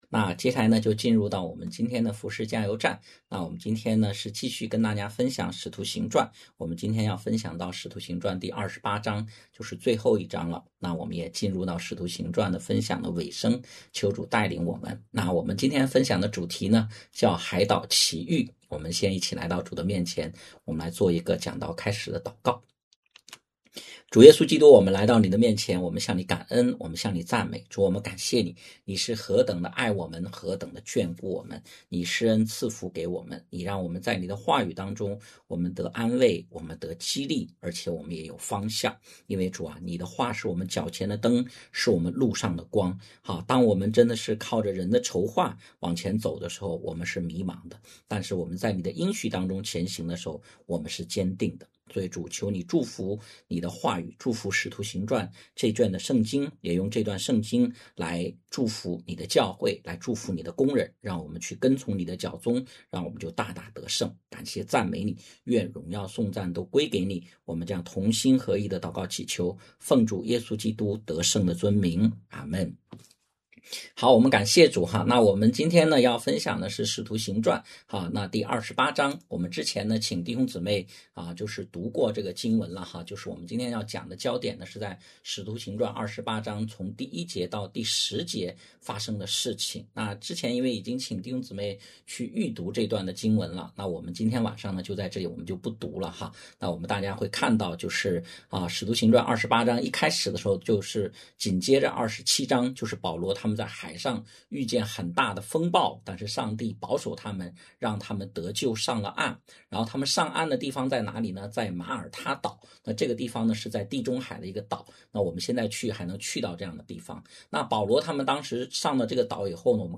讲道录音 点击音频媒体前面的小三角“►”就可以播放 https